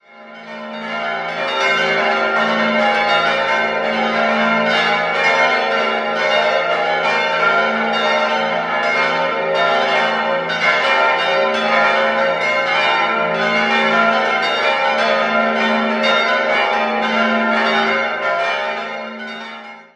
7-stimmiges Geläute: f'-as'-b'-c''-es''-f''-ges''
Das historische Geläute umfasst die Glocken as', c'', es'' und ges'', welche in den Jahren 1750 (es''/ges''), 1751 (c'') und 1753 (as') von Abraham Brandtmair und Franziskus Kern in Augsburg gegossen wurden.
Originelles, lebendiges Geläute. Die kleine Glocke ges'' wird durch die moderne Technik des Linearantriebs zum Schwingen gebracht.
Steingaden_Wieskirche.mp3